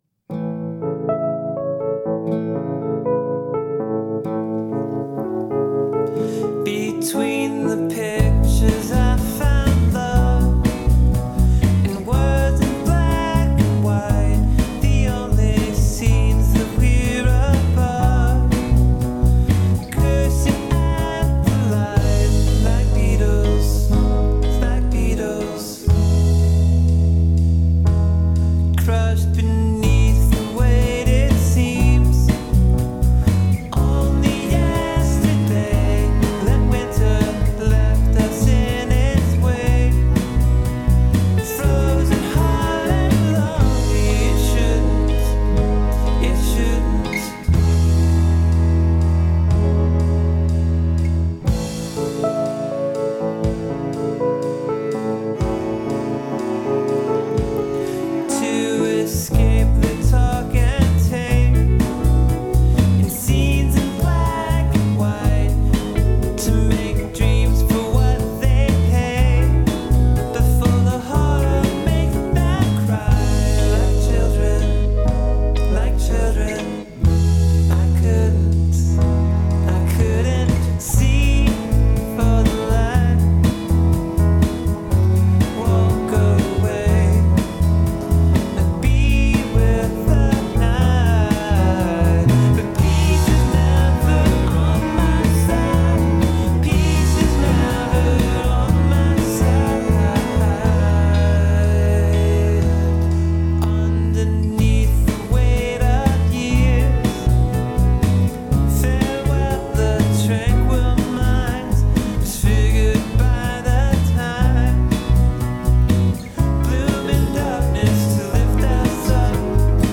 I'm branching out with the keyboards & cello, I nearly didn't put any guitar on this one, and the acoustic is wayy down in the mix.
This is slightly unfinished too, but it's a bit more polished and also NEW.